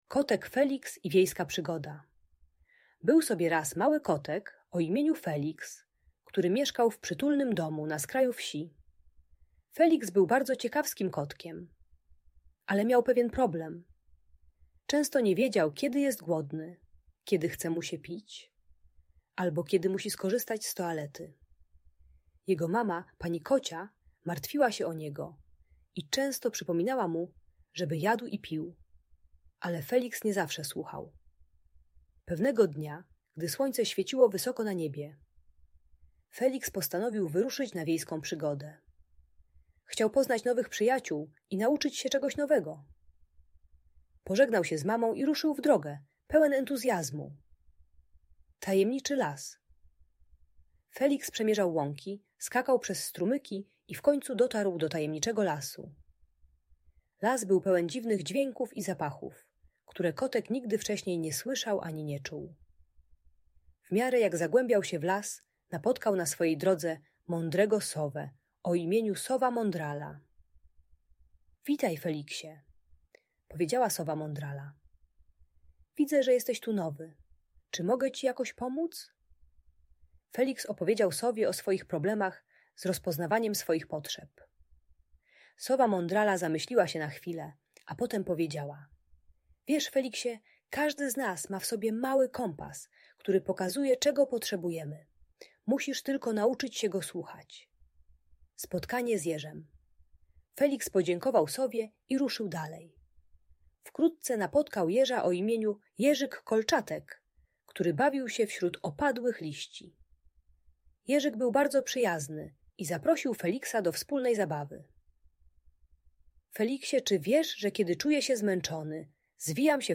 Kotek Feliks i Wiejska Przygoda - Audiobajka